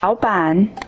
Sorry about that.